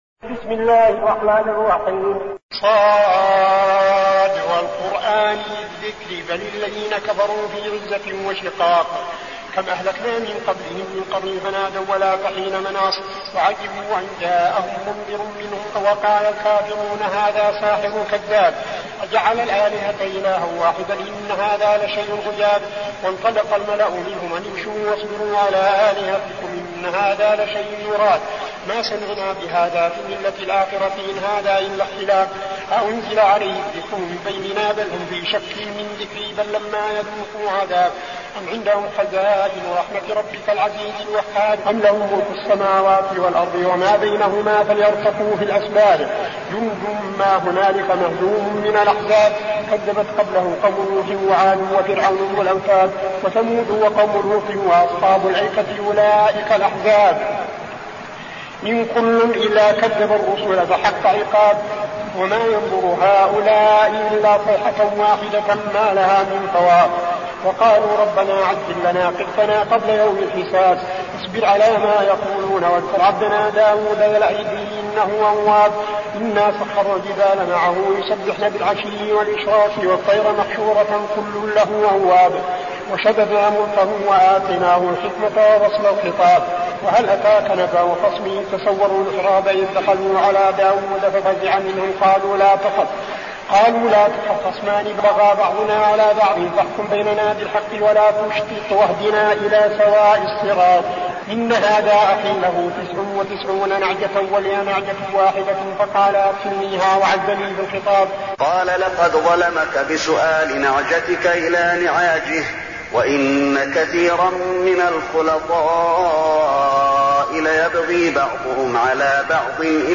المكان: المسجد النبوي الشيخ: فضيلة الشيخ عبدالعزيز بن صالح فضيلة الشيخ عبدالعزيز بن صالح ص